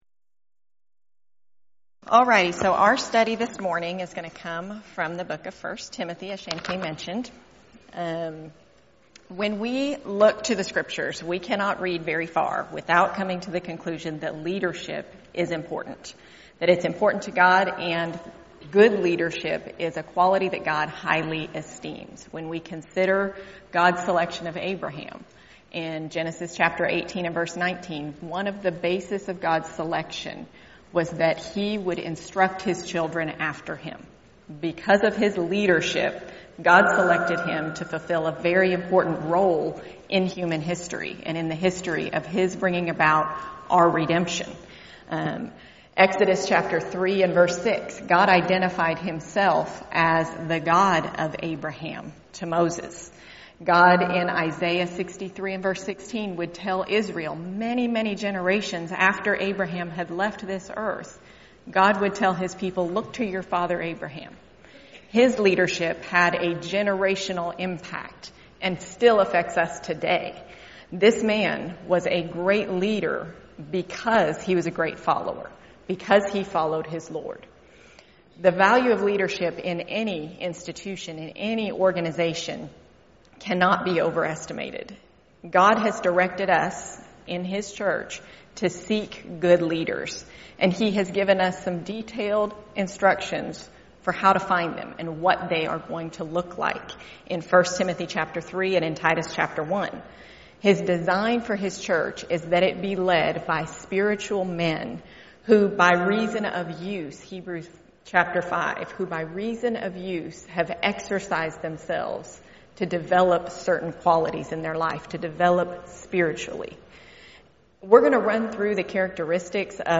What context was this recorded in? Event: 2019 Focal Point